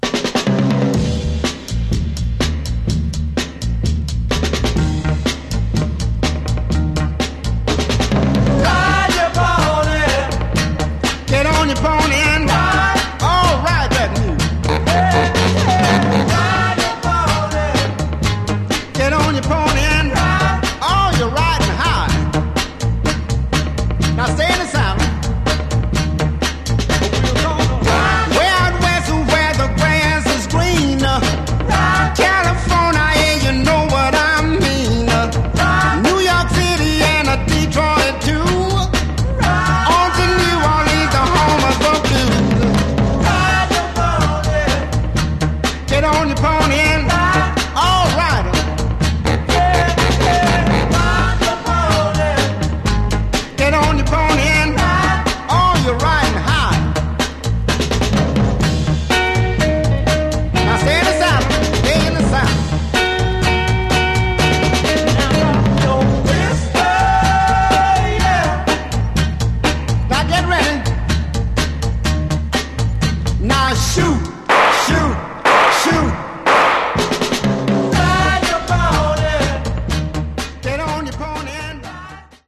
Genre: Funky Soul